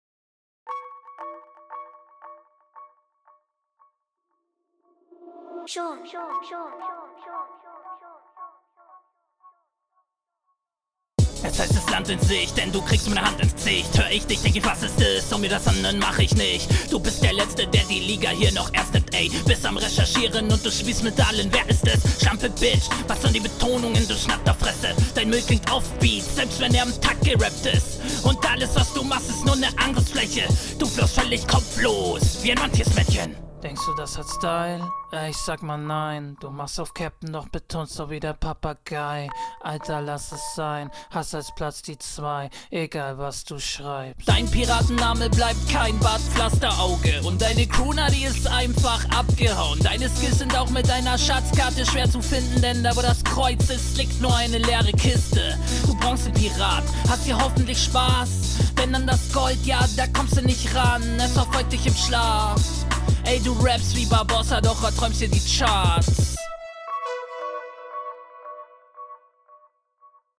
sehr gepresst fürs image aber das geht doch auch melodischer oder? textlich erste hälfte grandios, …